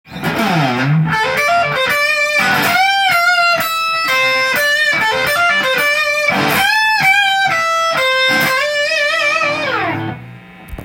このような往年の曲を弾いたり出来るので
boss.destotion3.m4a